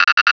Cri de Grainipiot dans Pokémon Diamant et Perle.